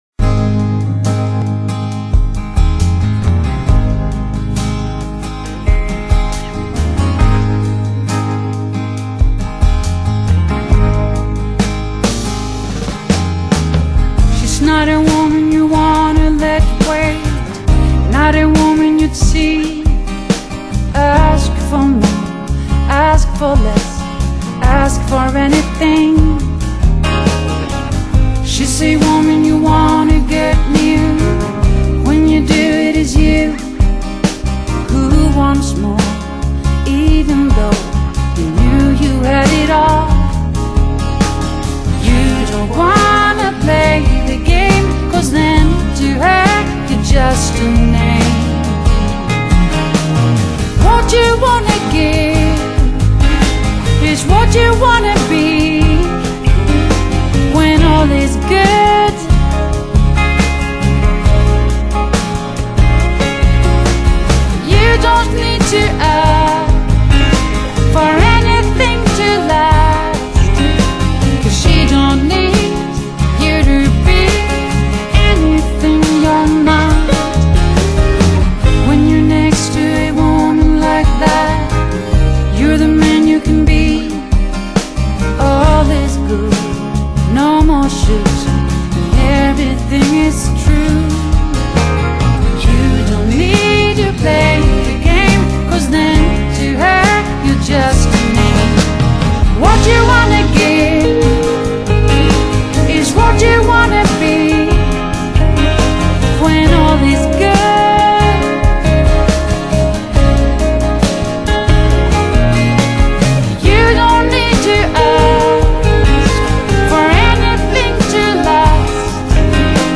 Blues And Jazz Para Ouvir: Clik na Musica.